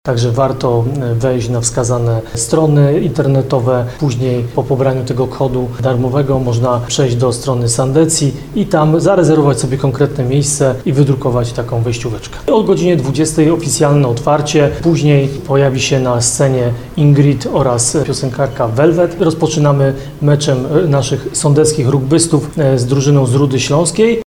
Będzie też mecz rugbystów Biało-Czarnych Nowym Sącz z rywalami z Rudy Śląskiej – mówi Ludomir Handzel, prezydent Nowego Sącza.